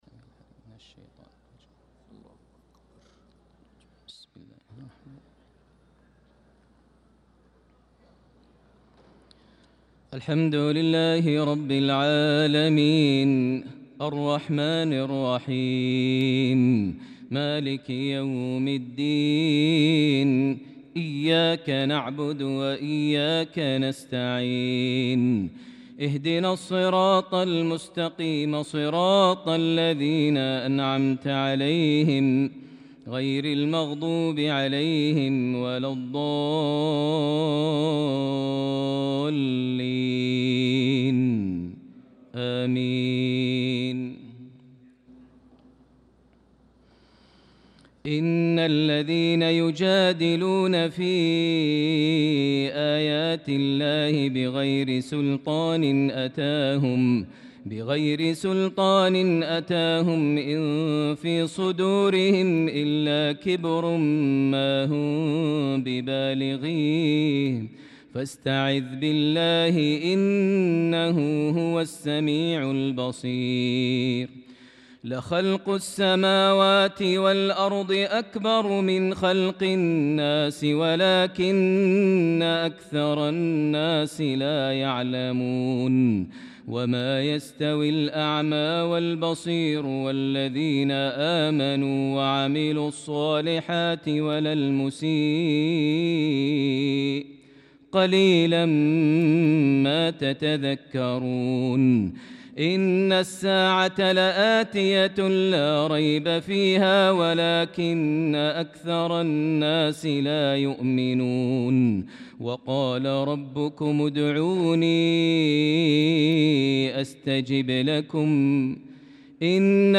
صلاة العشاء للقارئ ماهر المعيقلي 12 شوال 1445 هـ
تِلَاوَات الْحَرَمَيْن .